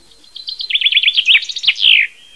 En av Sveriges vanligaste fåglar och finns där det är skog, under april hörs den flitigt sjunga.
bofink.wav